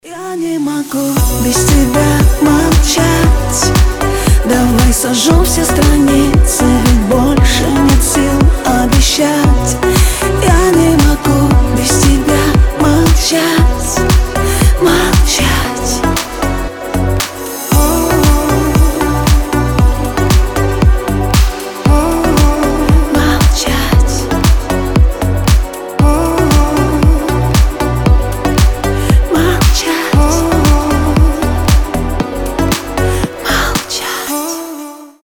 • Качество: 320, Stereo
поп
женский вокал
deep house